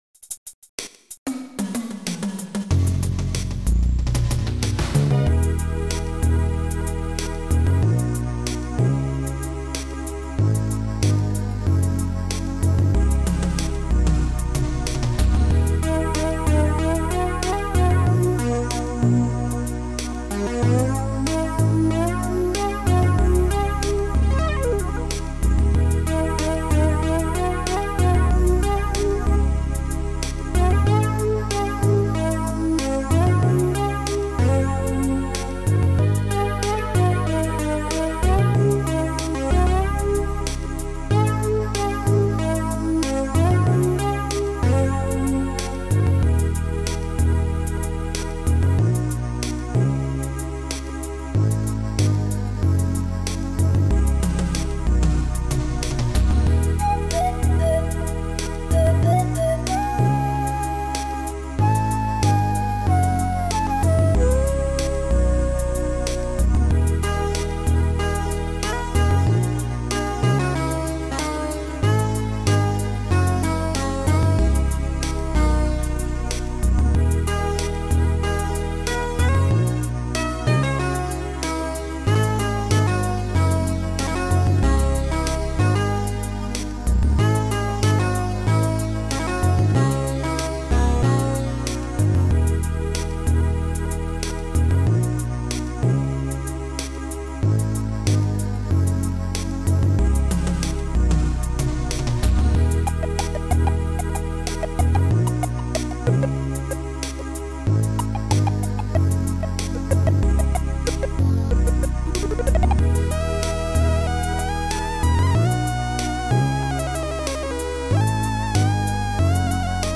Sound Format: Noisetracker/Protracker
Sound Style: Mellow